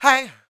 Index of /90_sSampleCDs/ILIO - Vocal Planet VOL-3 - Jazz & FX/Partition G/8 FEM PERC 2